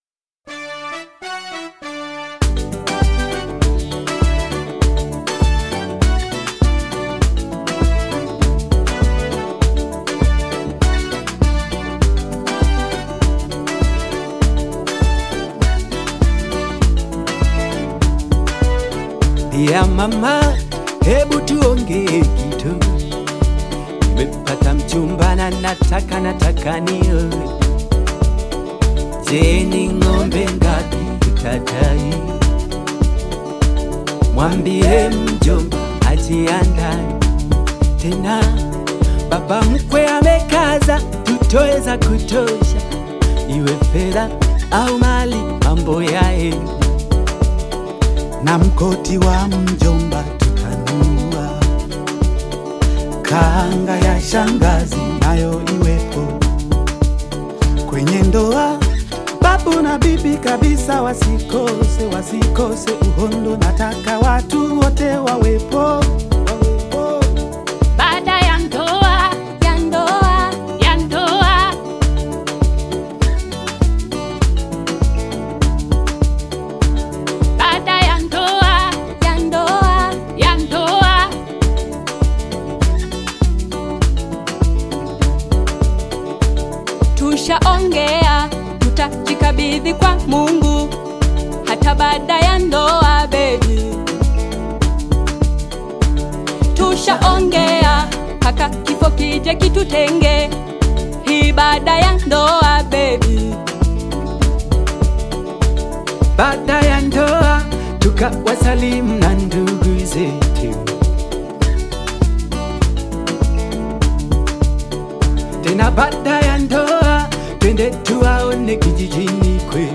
Tanzanian gospel choir
African Music